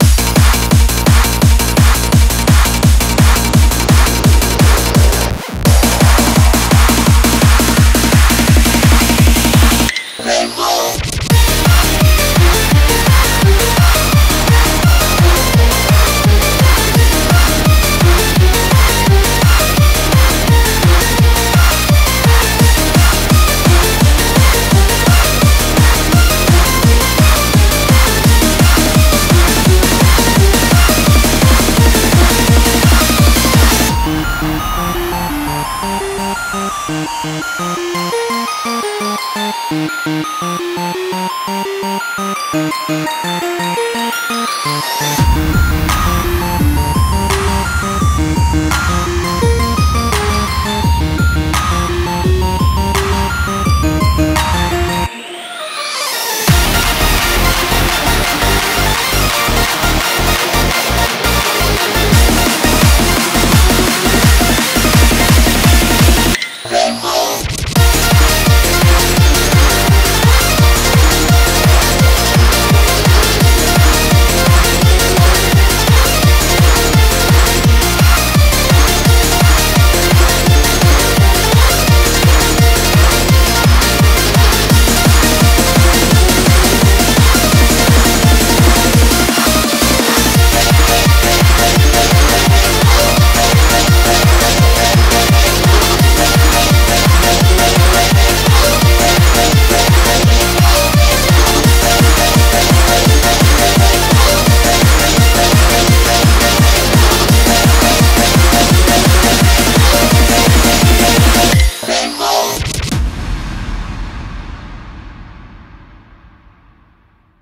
BPM85-170
Comments[SPORTS CORE]